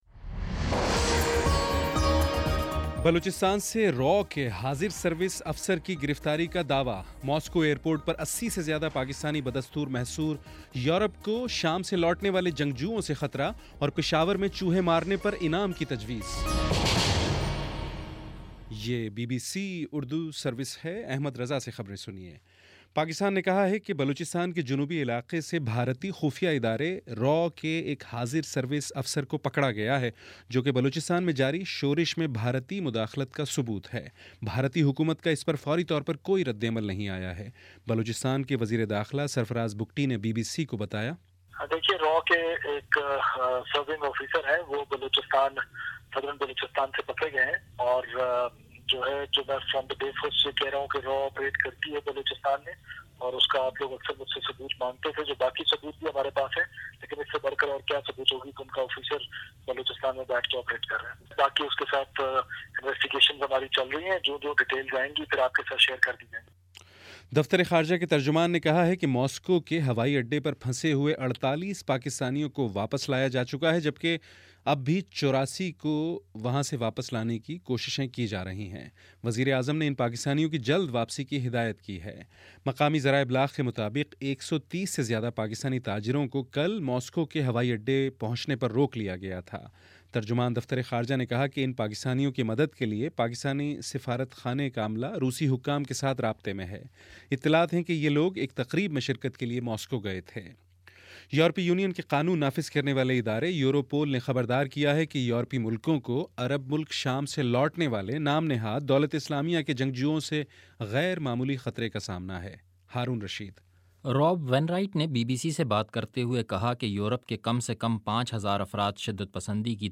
مارچ 24 : شام چھ بجے کا نیوز بُلیٹن